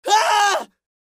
crying-men-sound